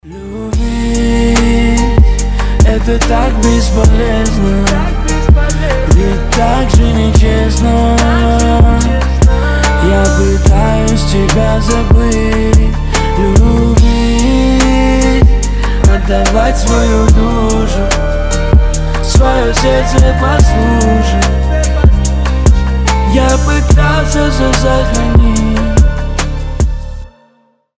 • Качество: 320, Stereo
красивые
лирика
дуэт
медленные